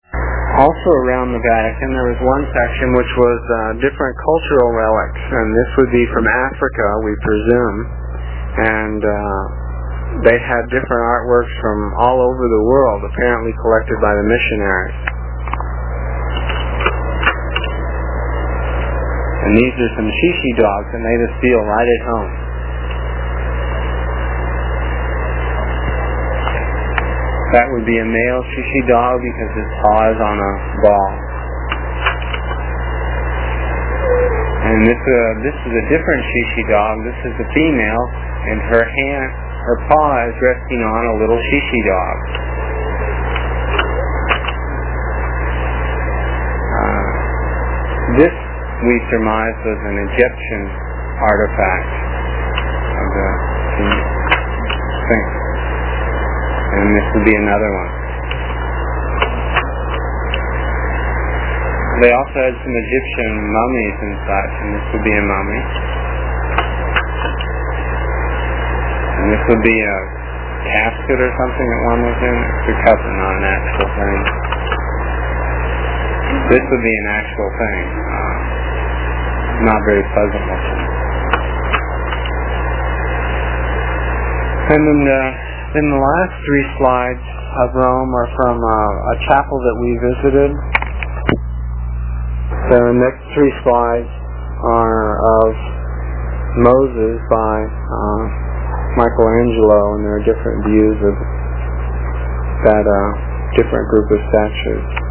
It is from the cassette tapes we made almost thirty years ago. I was pretty long winded (no rehearsals or editting and tapes were cheap) and the section for this page is about two minutes and will take about thirty seconds to download with a dial up connection.